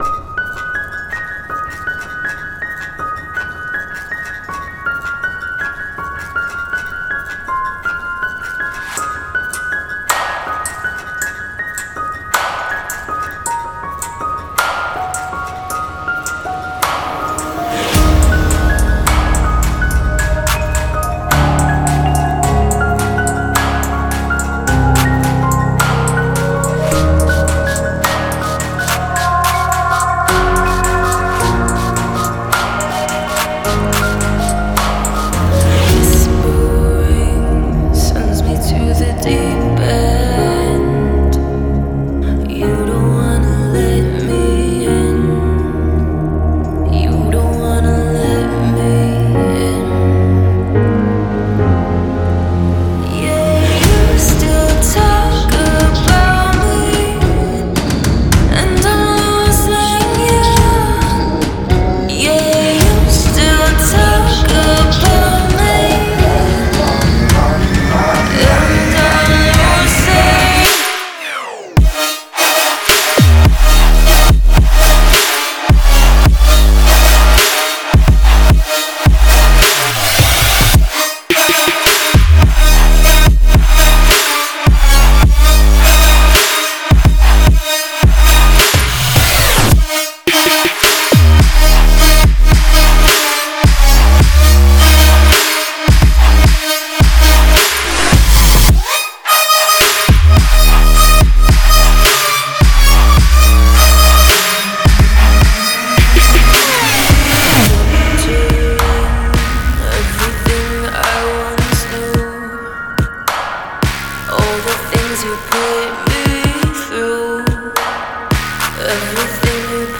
Dubstep, EDM, Electronic, Pop, Trap
Vocals